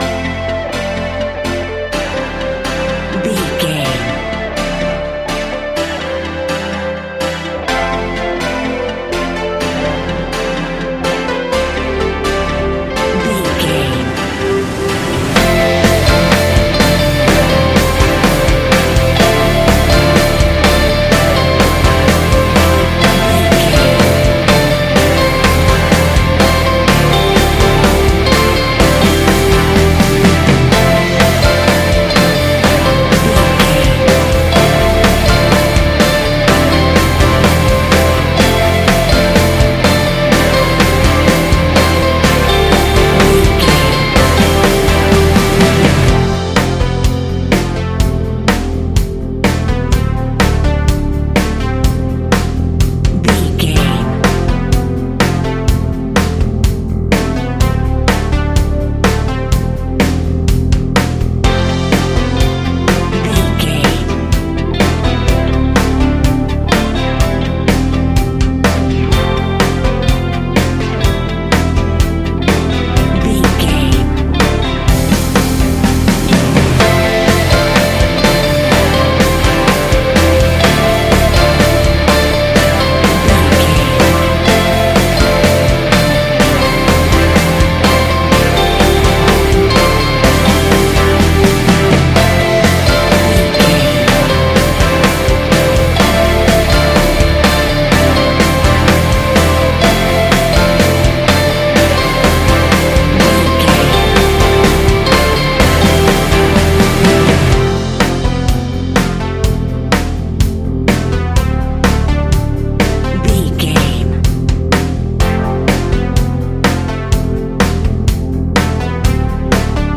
Ionian/Major
ambient
electronica
new age
downtempo
synth
pads